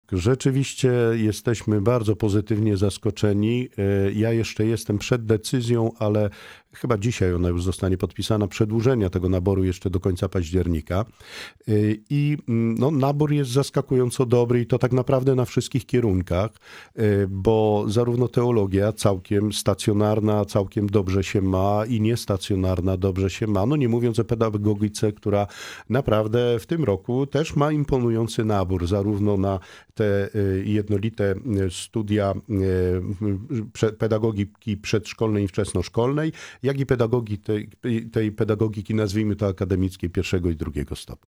Gościem audycji „Poranny Gość” był ks. prof. dr hab.